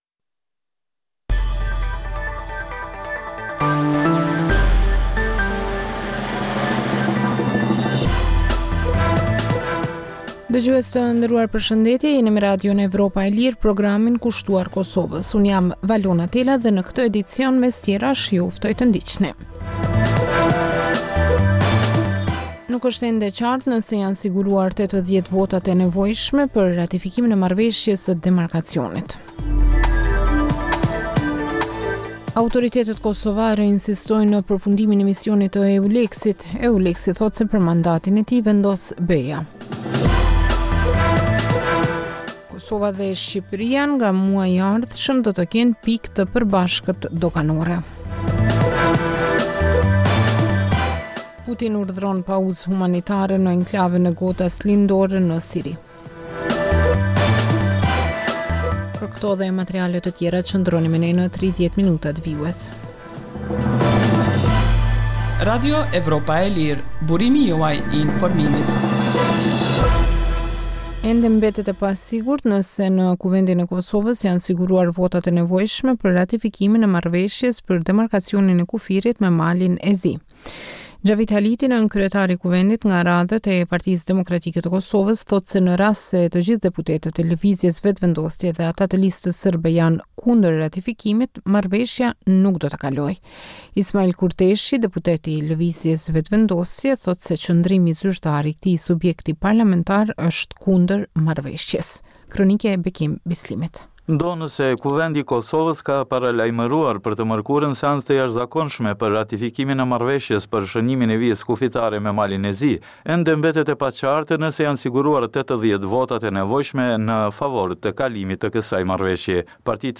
Emisioni i orës 21:00 është rrumbullaksim i zhvillimeve ditore në Kosovë, rajon dhe botë. Rëndom fillon me buletinin e lajmeve dhe vazhdin me kronikat për zhvillimet kryesore politike të ditës. Në këtë edicion sjellim intervista me analistë vendor dhe ndërkombëtar për zhvillimet në Kosovë, por edhe kronika dhe tema aktuale dhe pasqyren e shtypit ndërkombëtar.